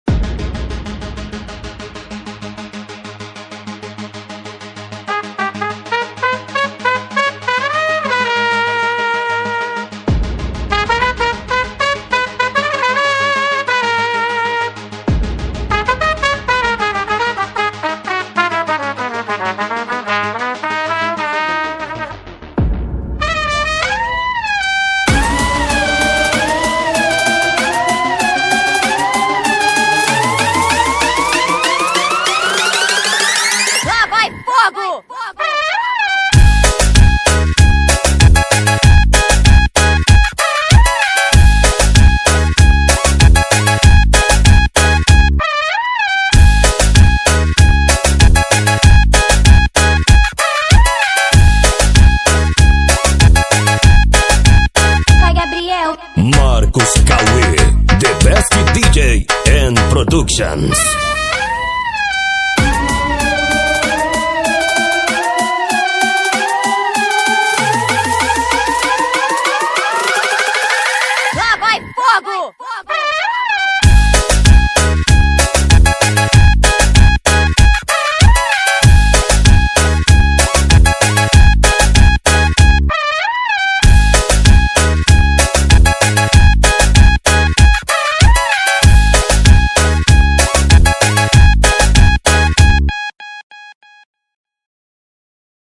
Tecnofunk